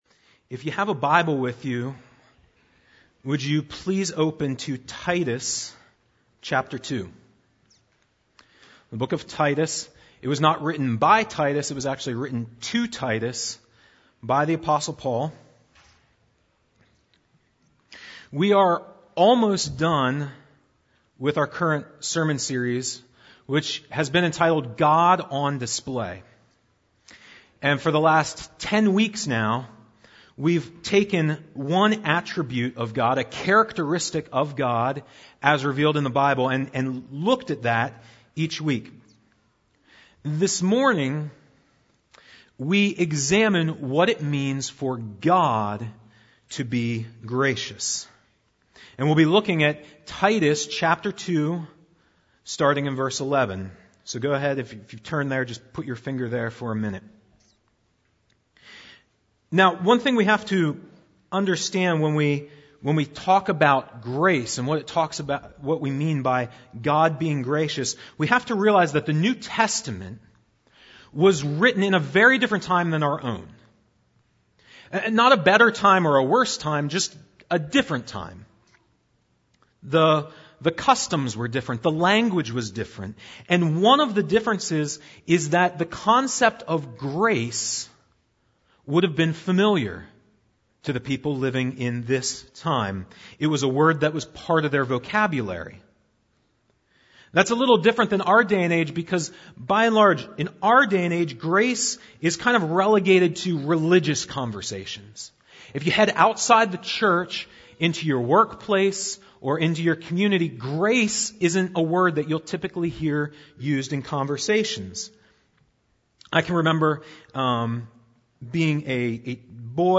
Download the weekly Bible Study that goes with this sermon.